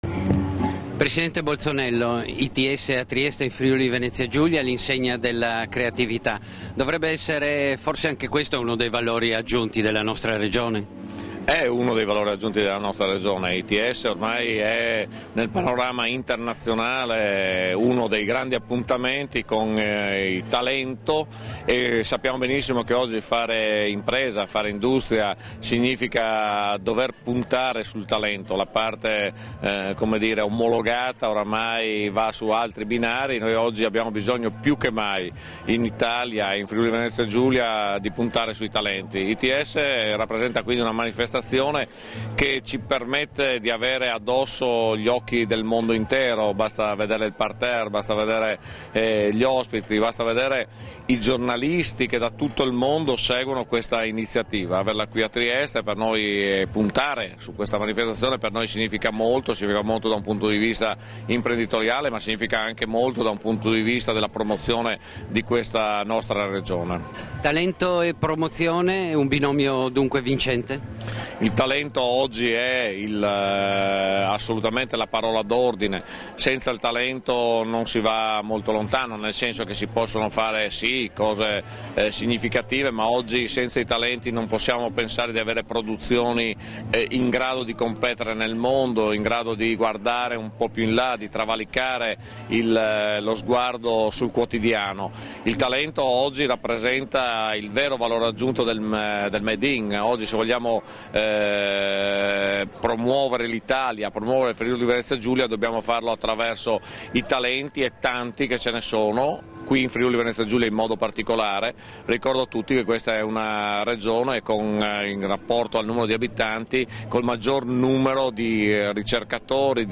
Dichiarazioni di Sergio Bolzonello (Formato MP3)
rilasciate alla serata finale di Its–International Talent Support 2014, nel Salone degli Incanti della Vecchia Pescheria a Trieste il 12 luglio 2014